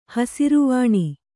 ♪ hasiru vāṇi